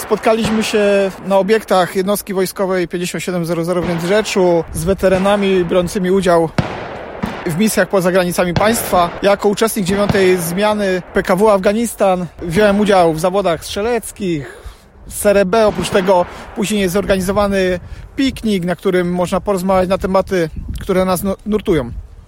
mówi jeden z weteranów